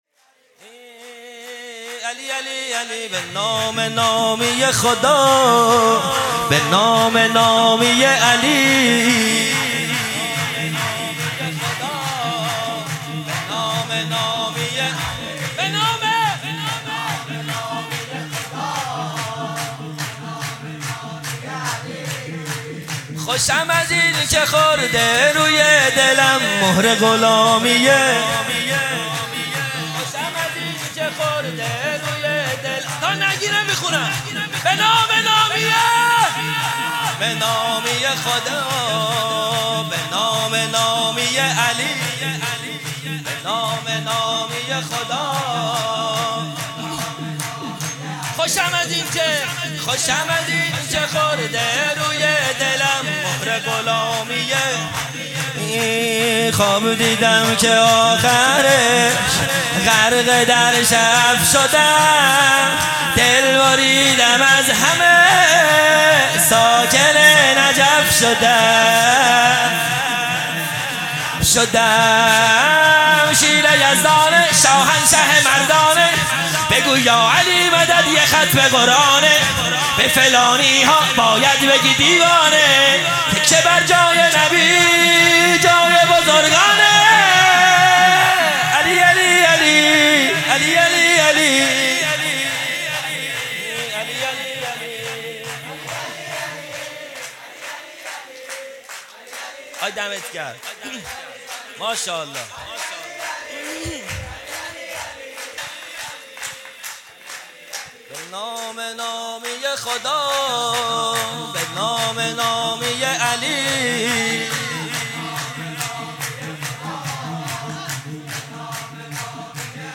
0 0 سرود دوم